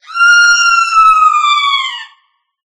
Scream.ogg